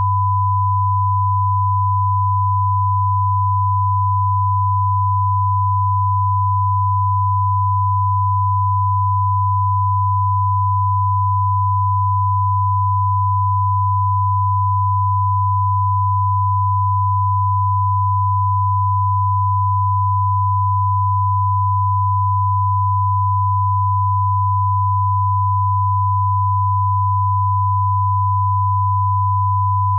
These test files contain -10dBFS 1kHz tone on the left channel and 100Hz tone on the right channel.
48k_2ch_16_tones.wav